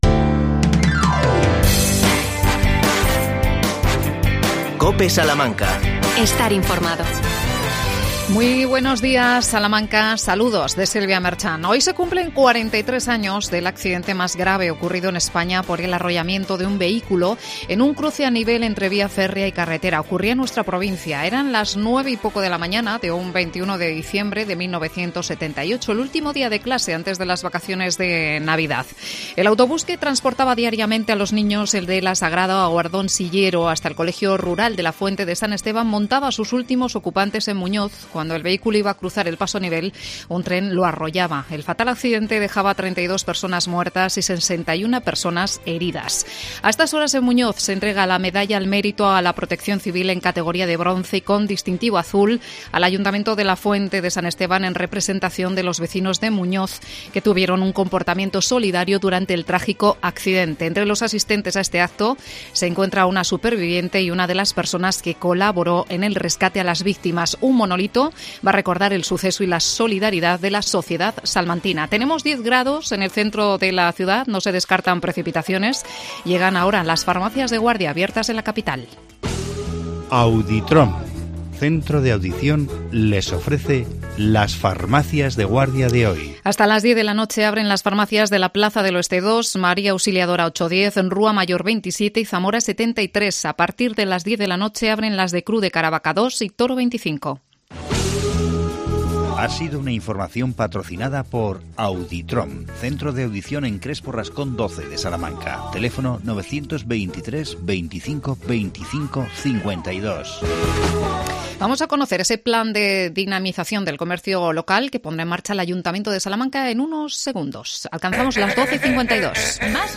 AUDIO: Plan de Dinamización del Comercio Local. Entrevista al concejal de Comercio Juan José Sánchez.